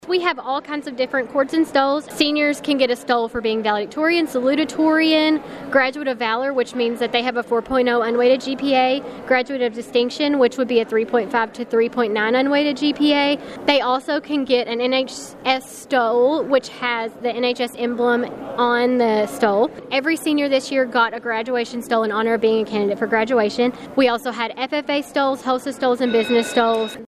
The senior class of Caldwell County High School received their graduation cords and stoles Monday morning in a special presentation in the high school Fine Arts Building.